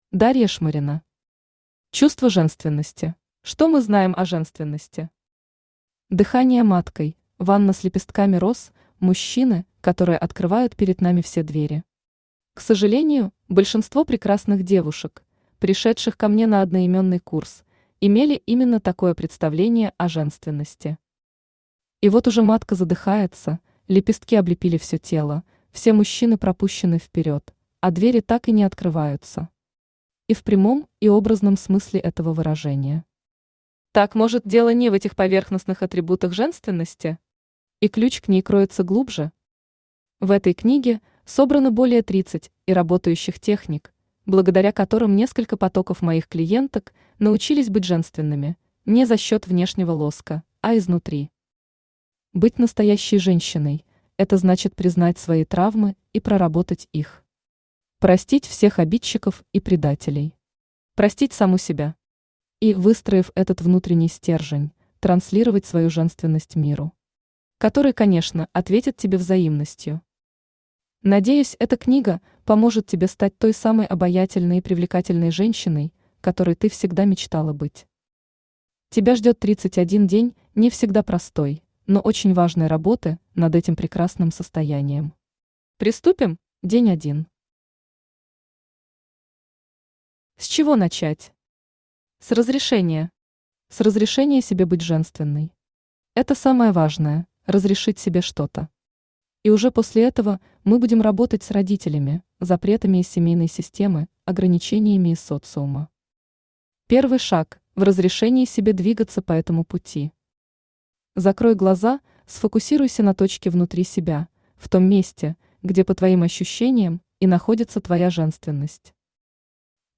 Аудиокнига Чувство женственности | Библиотека аудиокниг
Читает аудиокнигу Авточтец ЛитРес.